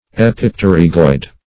Search Result for " epipterygoid" : The Collaborative International Dictionary of English v.0.48: Epipterygoid \Ep`ip*ter"y*goid\, a. [Pref. epi- + pterygoid.]
epipterygoid.mp3